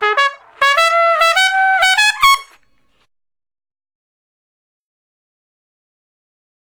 Solos are also in multiple keys.
Trumpet Solo